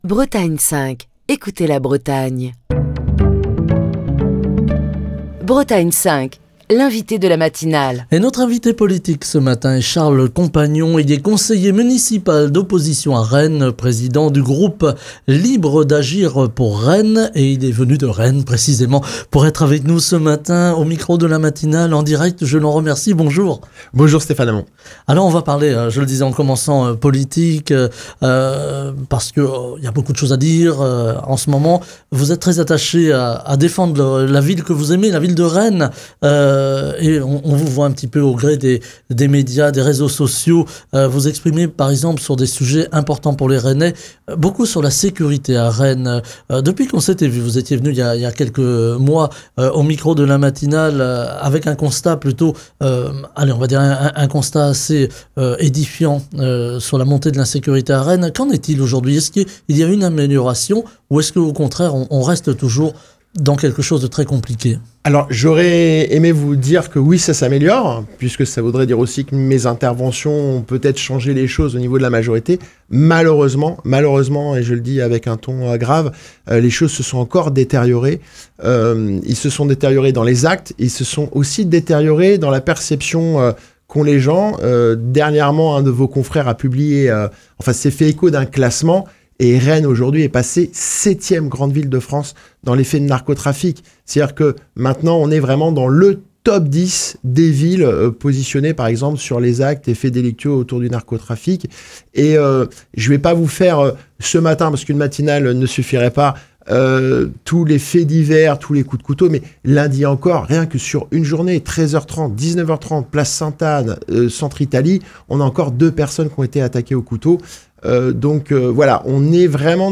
Ce vendredi, Charles Compagnon, conseiller municipal d'opposition à Rennes et président du groupe Libres d'Agir pour Rennes, était l'invité politique de la matinale sur Bretagne 5.